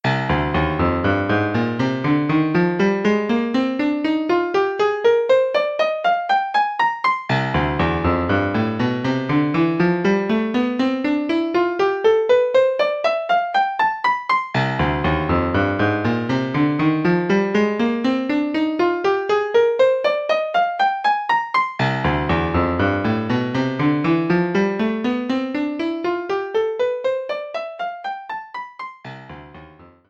Im zweiten Beispiel sorgt ein Ring für stetigen Wechsel zwischen Moll und Dur.
moll_dur_up.mp3